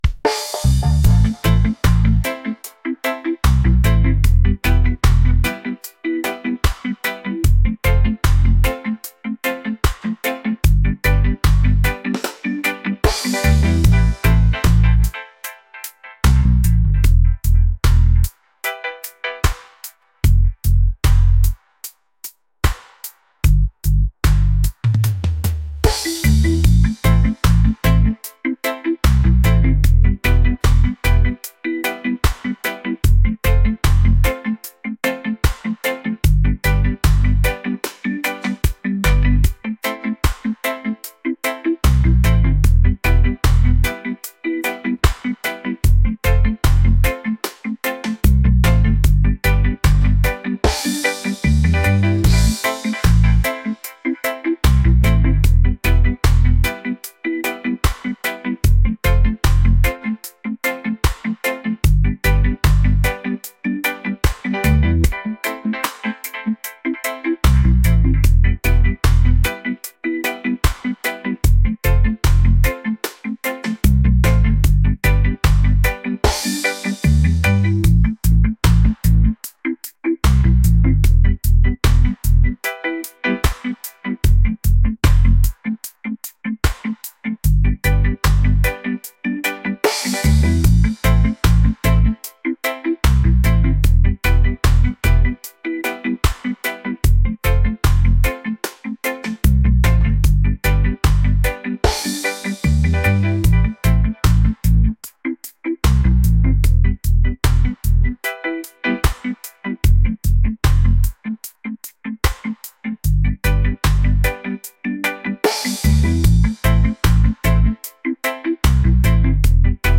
laid-back | romantic | reggae